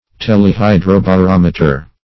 Search Result for " telehydrobarometer" : The Collaborative International Dictionary of English v.0.48: Telehydrobarometer \Tel`e*hy`dro*ba*rom"e*ter\, n. [Gr. th^le far + hydrobarometer.] An instrument for indicating the level of water in a distant tank or reservior.